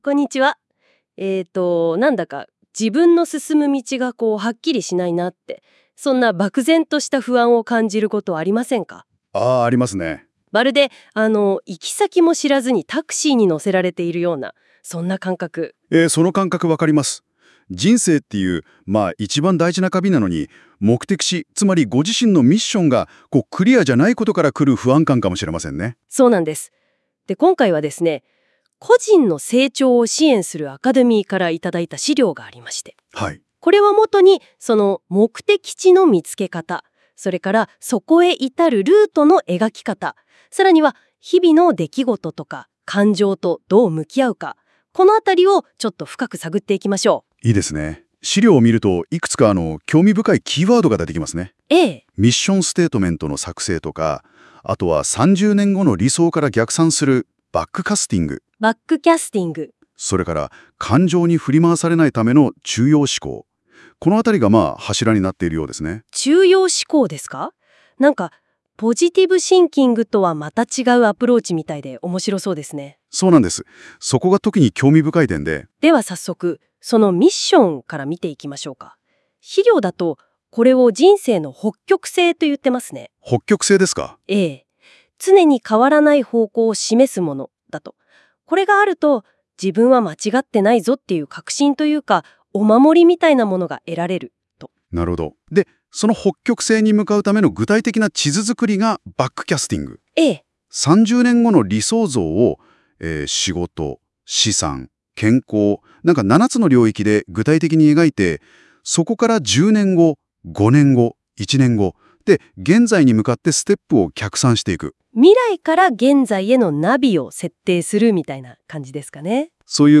すると、AIがその内容を分析し、まるで講師がポイントを解説してくれるかのような「音声概要」を生成してくれるんです。
しばらく待つと、NotebookLMが資料の内容を分析し、2つのAIによる音声解説（まるでポッドキャストを聞いているよう！）を生成してくれますので、後は聞くだけ！神！